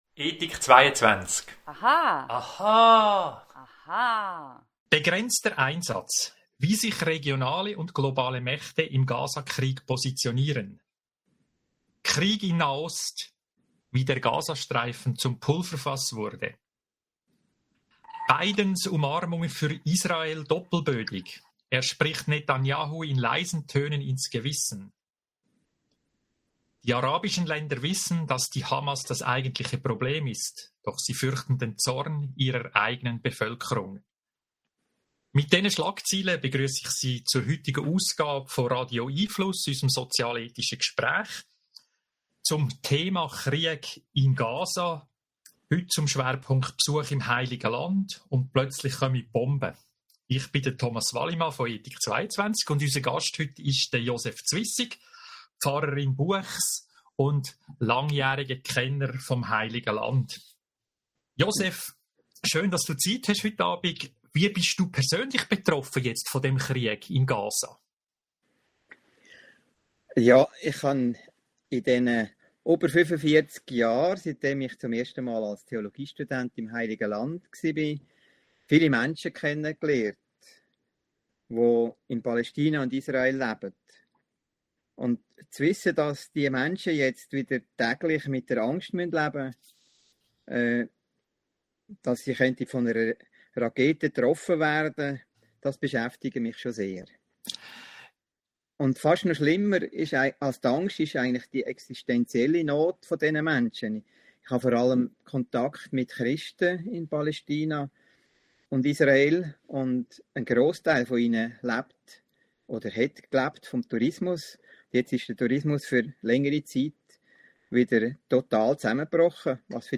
Weitere interessante Gedanken zum Thema hören Sie im Podcast unseres Gesprächs vom 1. November 2023